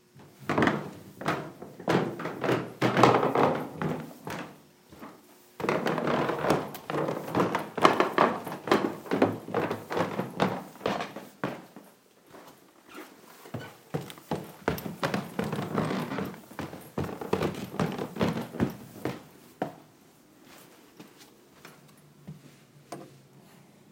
吱吱作响的木制台阶，下了又上
描述：上下一个吱吱作响的楼梯
Tag: 楼梯 台阶 起来 叽叽嘎嘎声 脚步声 楼梯